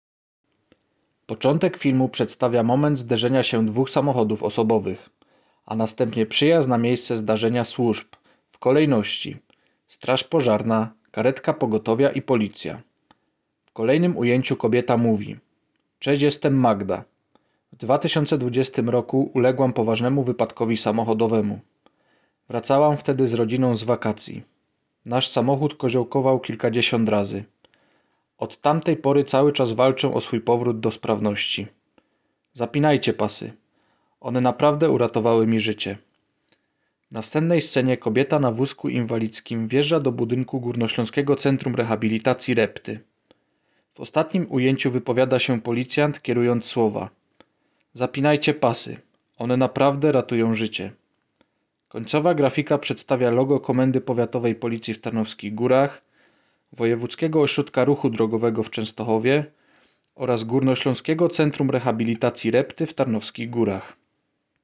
Nagranie audio audiodeskrypcja_spot.m4a